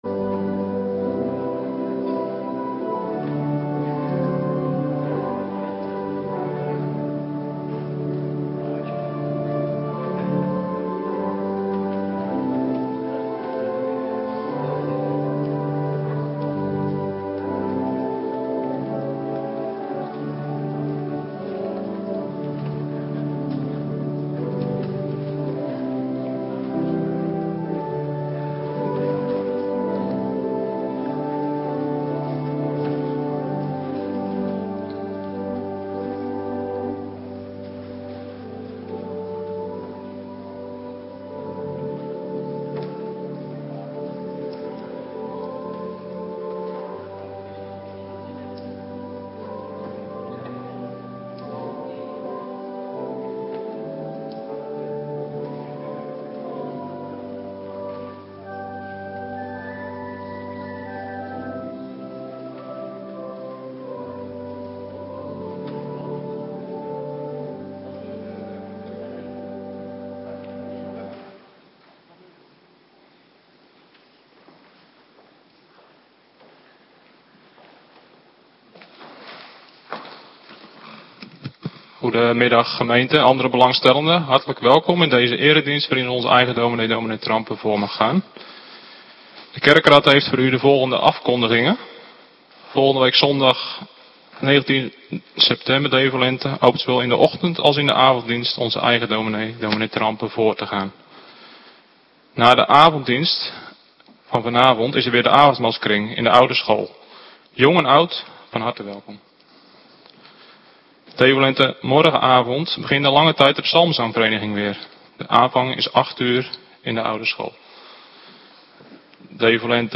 Middagdienst Heilig Avondmaal